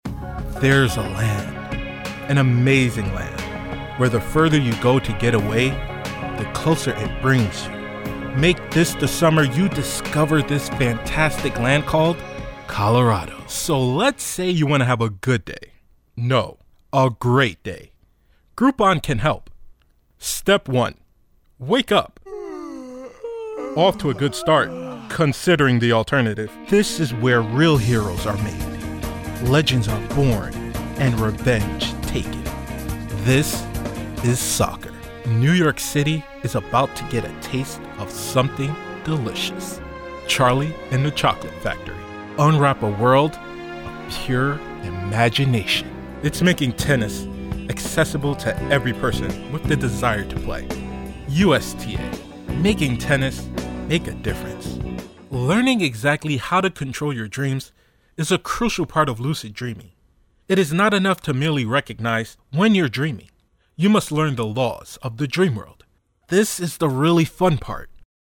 VOICE OVER DEMO